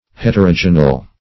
Heterogeneal \Het`er*o*ge"ne*al\, a.